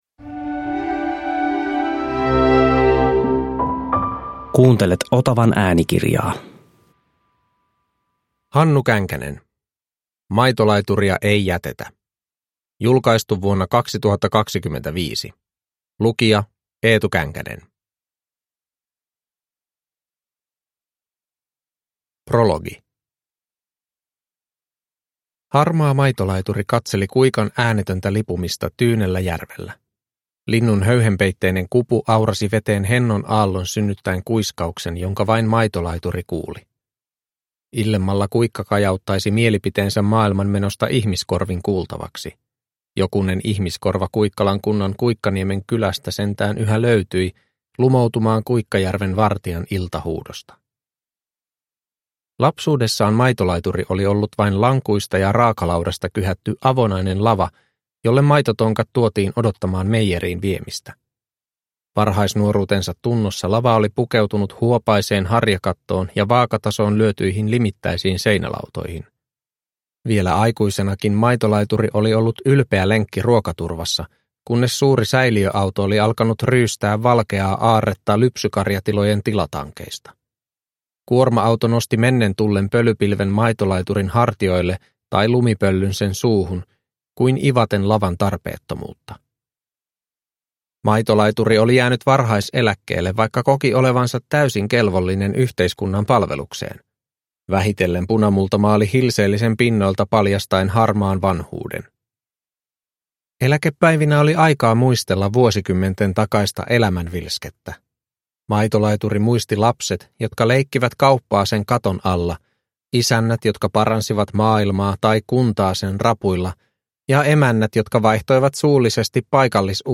Kiltin miehen kiusaukset 1–3 – Ljudbok